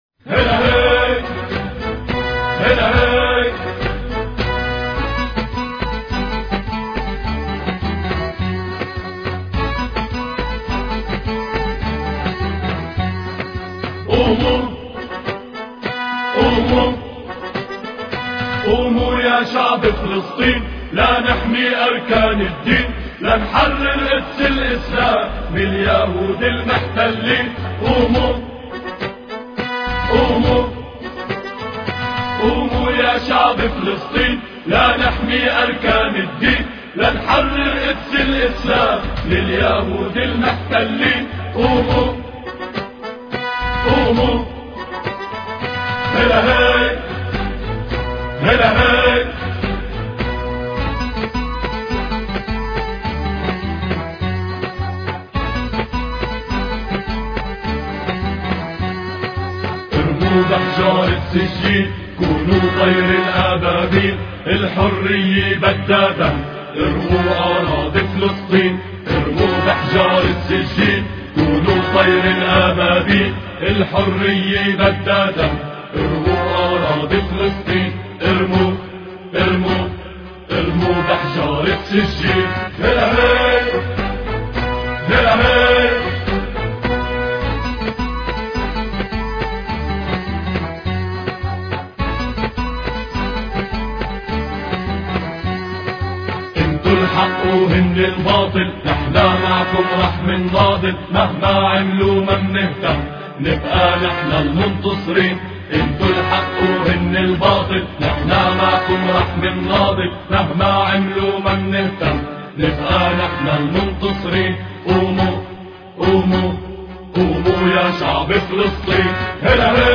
قوموا الثلاثاء 6 فبراير 2007 - 00:00 بتوقيت طهران تنزيل الحماسية شاركوا هذا الخبر مع أصدقائكم ذات صلة الاقصى شد الرحلة أيها السائل عني من أنا..